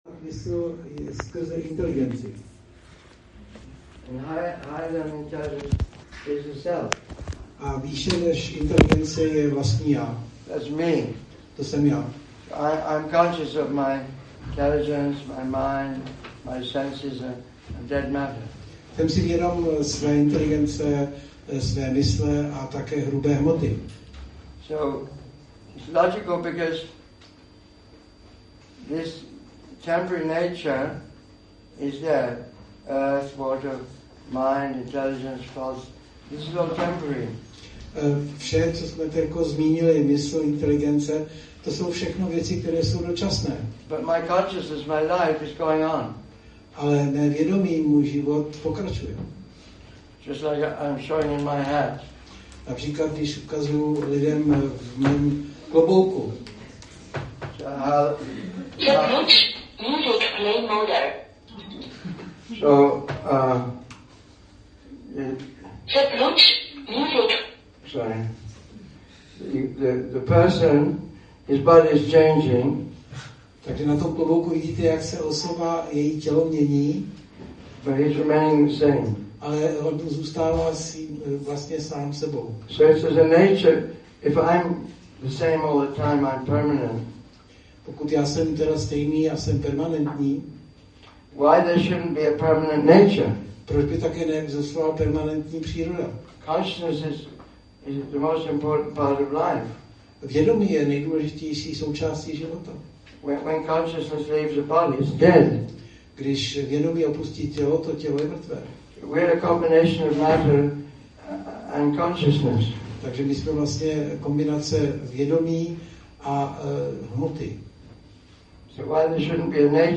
Přednáška – Góvinda